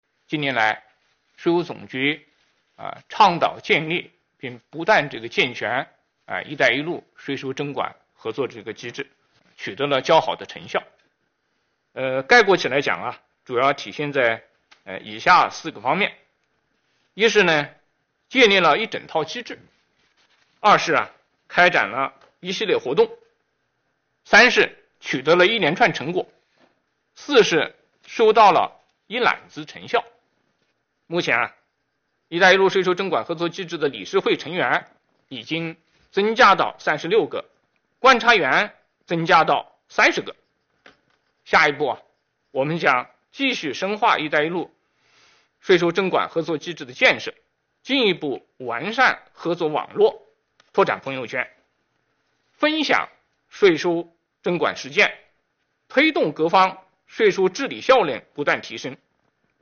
近日，国务院新闻办公室举行新闻发布会，国家税务总局相关负责人介绍《关于进一步深化税收征管改革的意见》有关情况。会上，国家税务总局党委委员、总经济师王道树表示，“一带一路”税收征管合作机制理事会成员已增加至36个。